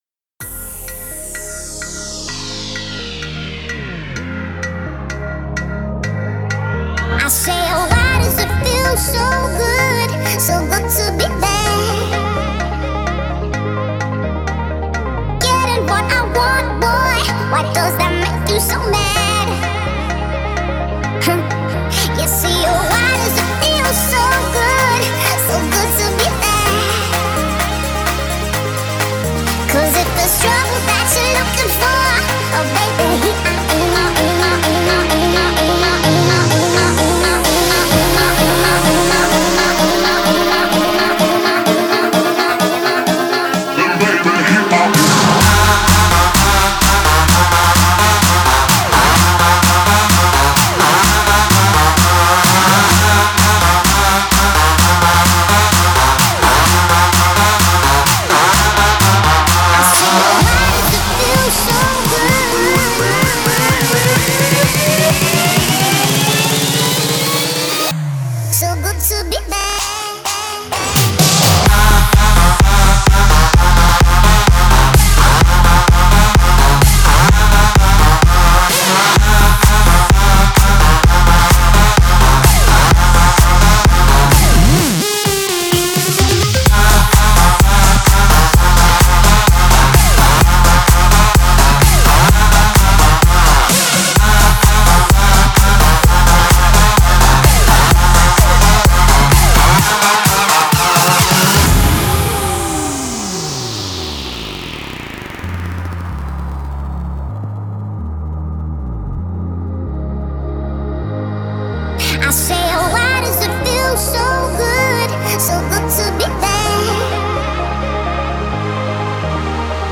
Категория: Электро музыка » Электро-хаус